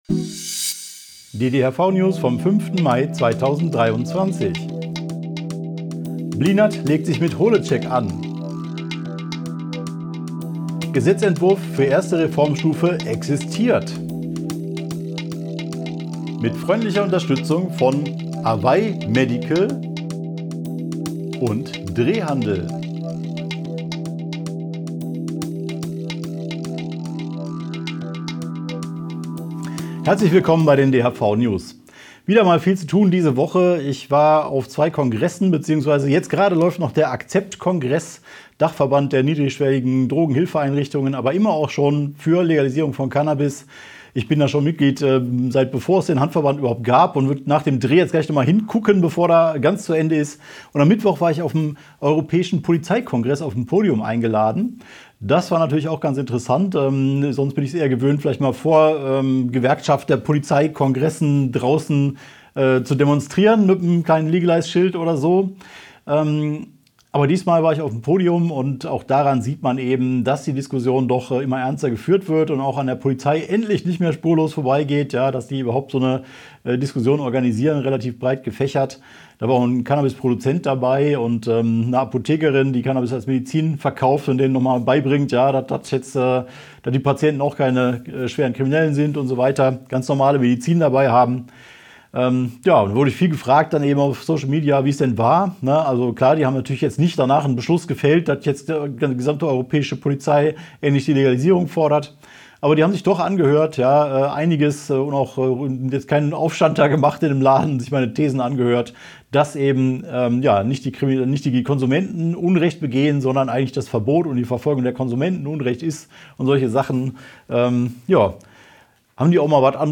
DHV-Video-News #379 Die Hanfverband-Videonews vom 05.05.2023 Die Tonspur der Sendung steht als Audio-Podcast am Ende dieser Nachricht zum downloaden oder direkt hören zur Verfügung.